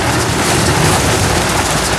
tyres_gravel_roll.wav